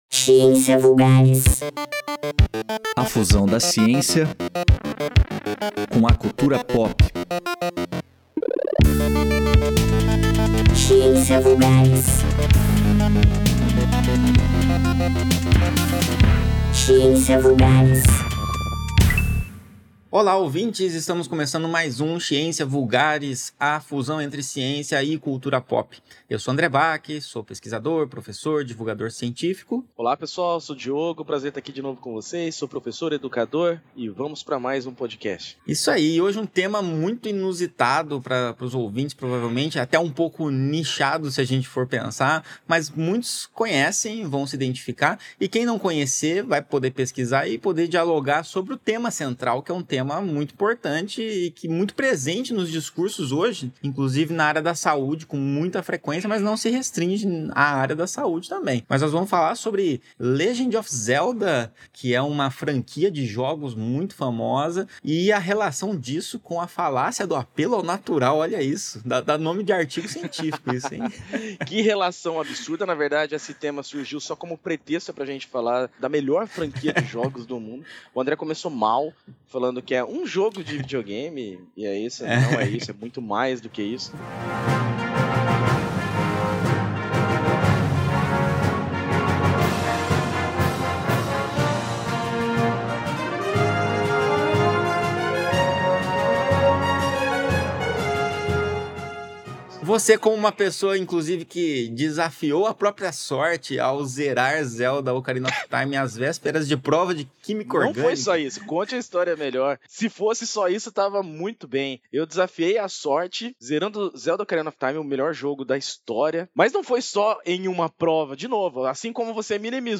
Iniciamos o episódio com um bate-papo descontraído, relembrando momentos memoráveis dos jogos e como “Zelda” se tornou um marco na cultura pop. Em seguida, avançamos para uma discussão mais profunda sobre a falácia do apelo ao natural, esclarecendo o que ela significa e como se manifesta em diferentes esferas da vida, desde a publicidade de produtos até argumentos sobre saúde e medicina, com um foco particular nos jogos mais recentes da franquia.